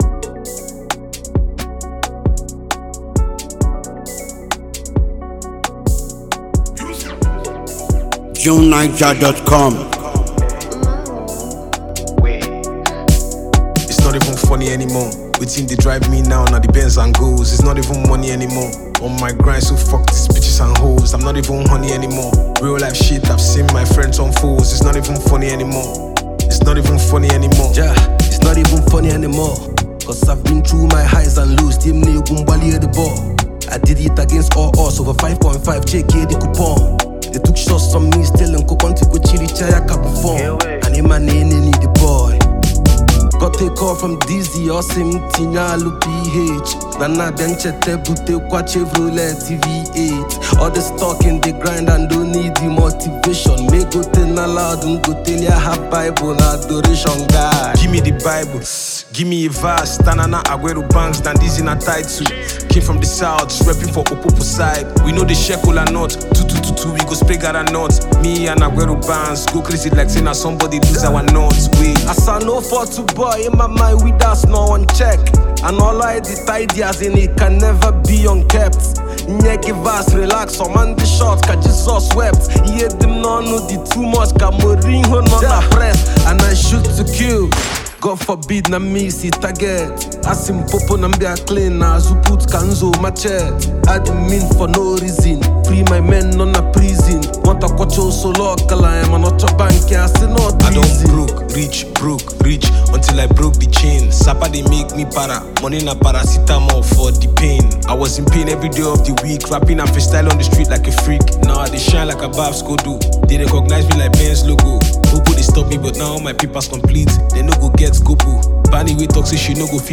streetwise rap